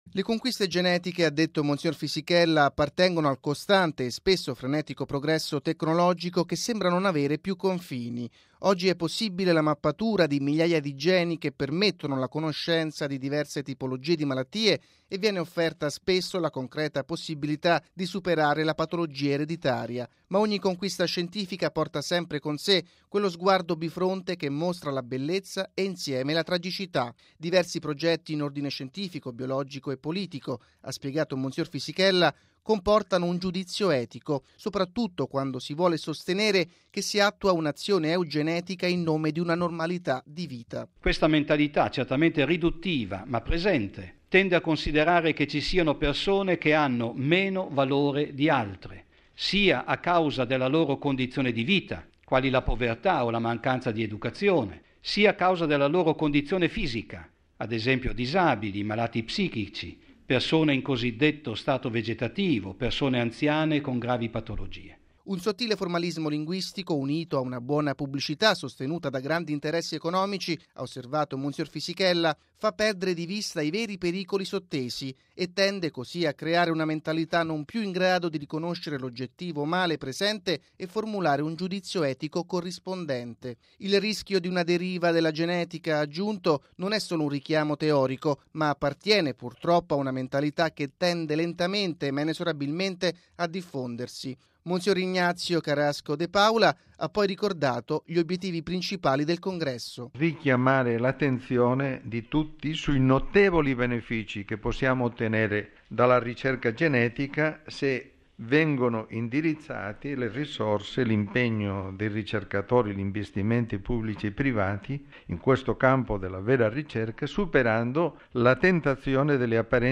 L'avvenimento, promosso dalla Pontificia Accademia per la Vita, è stato presentato stamani nella Sala Stampa della Santa Sede.